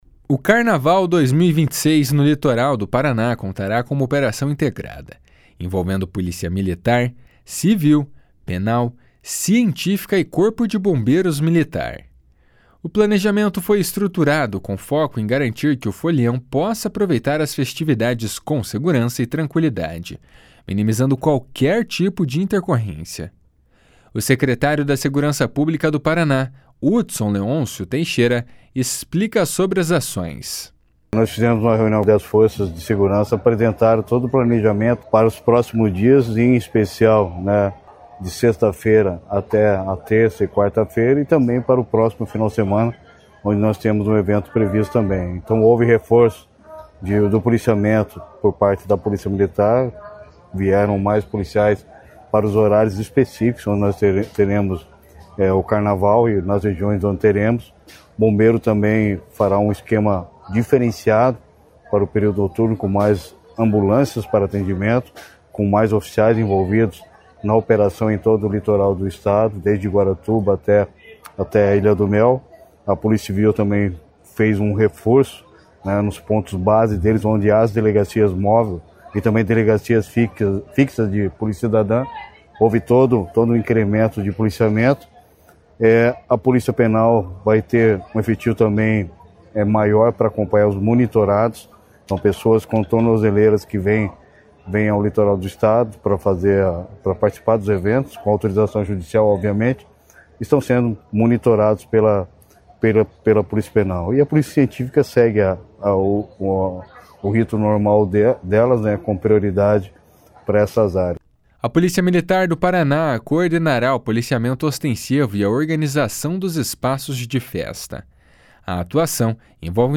O secretário da Segurança Pública do Paraná, Hudson Leôncio Teixeira, explica sobre as ações.